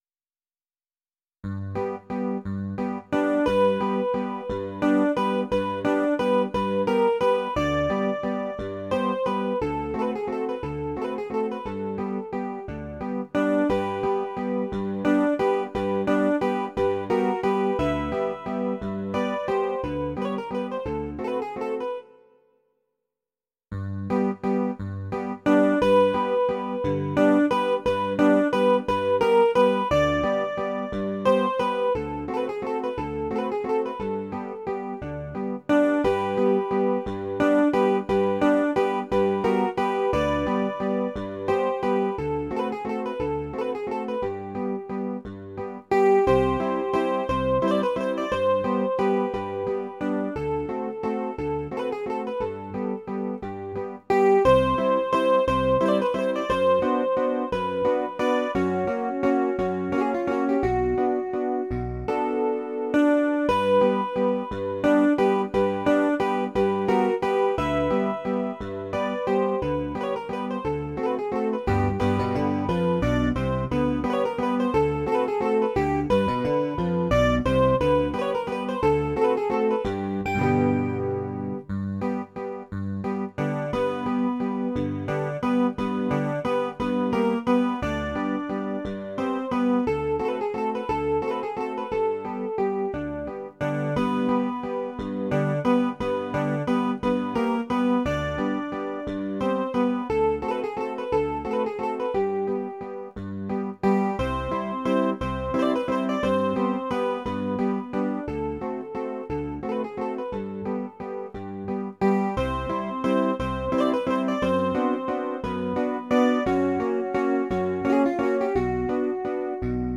歌劇~椿姫~より「乾杯の歌」ギター合奏に編曲
ましてアマチュア合奏グループなので無理せず、不本意ながらこのあたりで納得することにした。